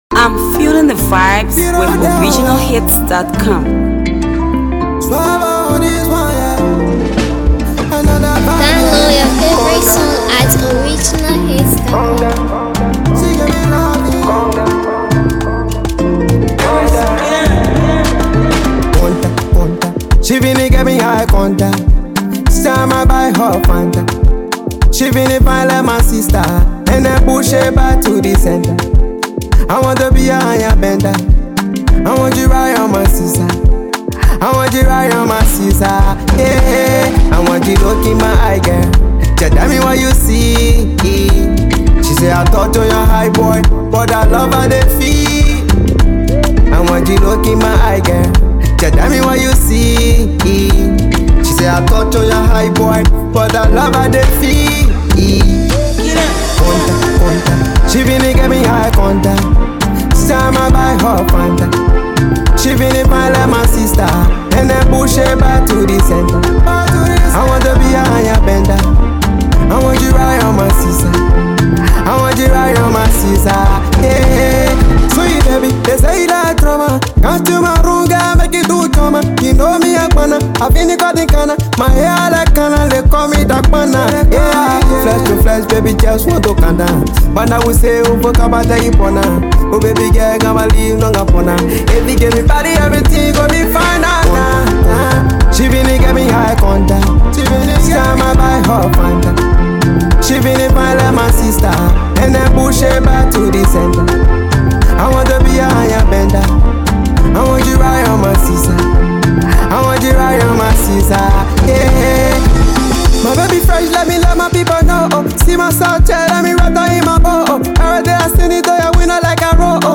studio effort